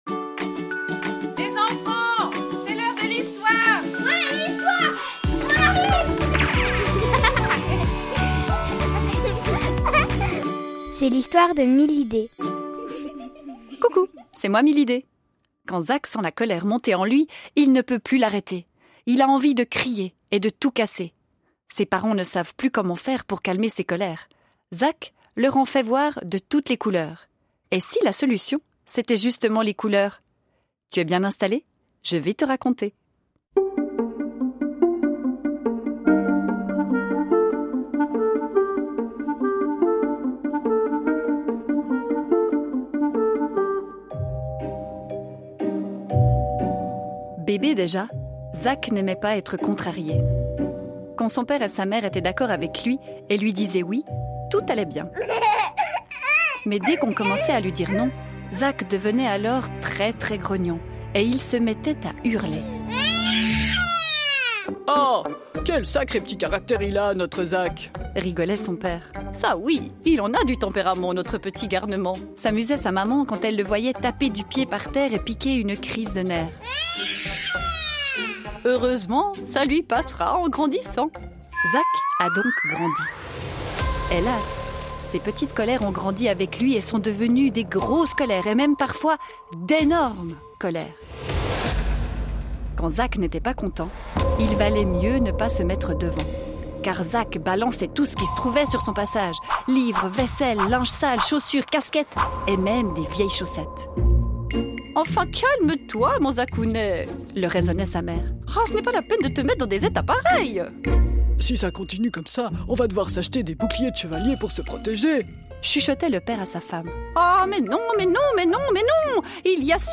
Une histoire à écouter à propos de la colère des enfants